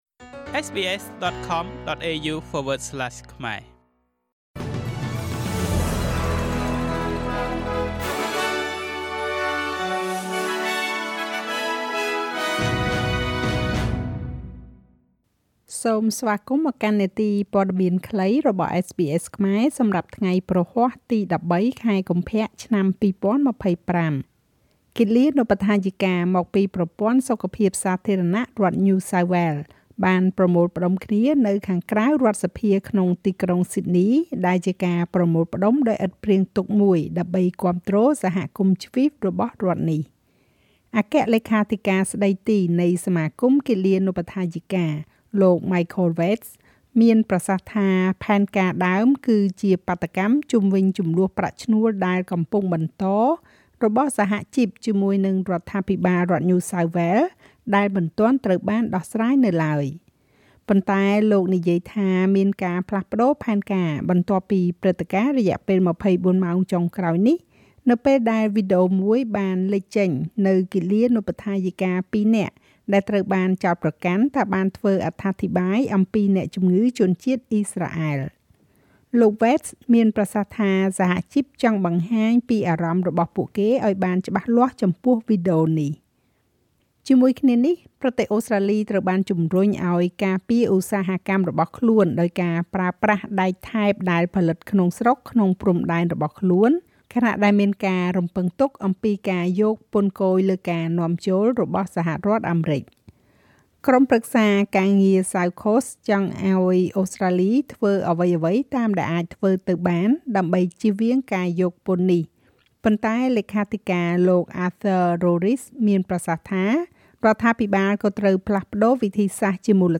នាទីព័ត៌មានខ្លីរបស់SBSខ្មែរ សម្រាប់ថ្ងៃព្រហស្បតិ៍ ទី១៣ ខែកុម្ភៈ ឆ្នាំ២០២៥